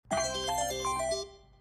big-win.mp3